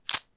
Gain_Currency.ogg